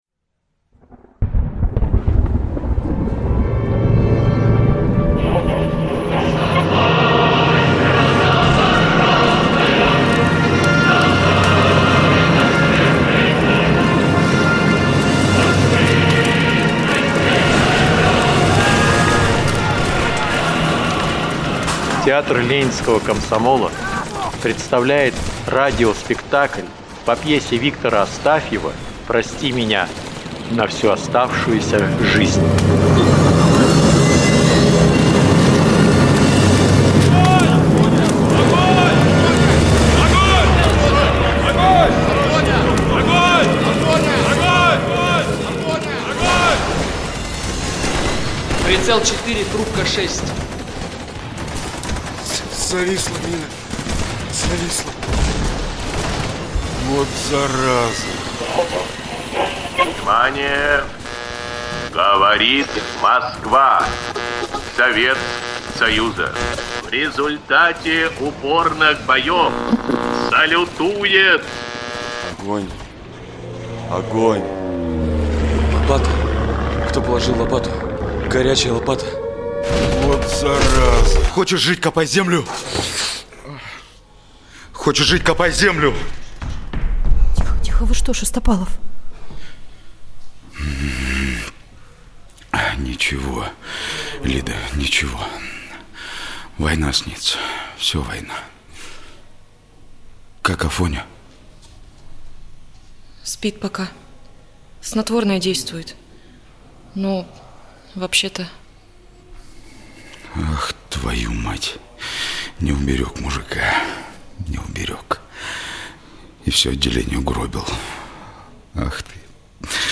Работа артистов из Прокопьевска оказалась настолько яркой, выразительной, трогательной, что мы просто не можем ею не поделиться с нашими читателями и подписчиками.